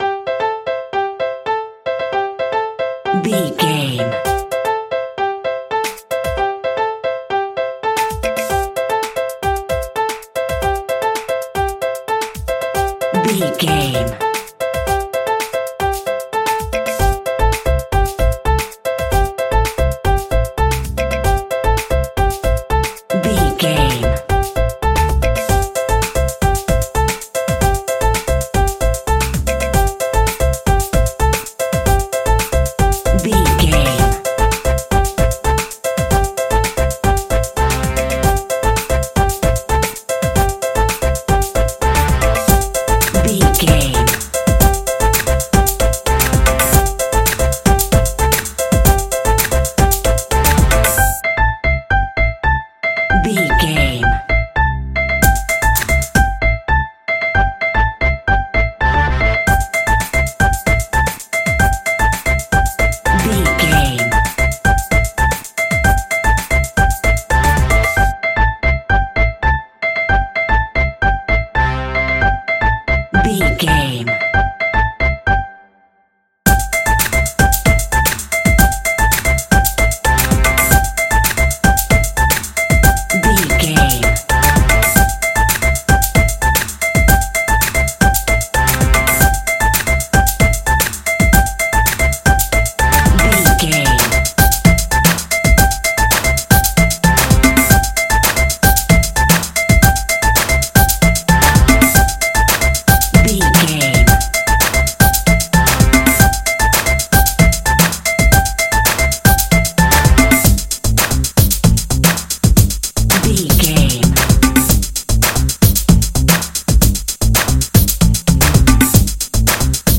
Ionian/Major
Caribbean
tropical
ethnic percussion